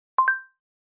Звуки Ok Google
Доступны разные варианты звуковых сигналов ассистента в хорошем качестве.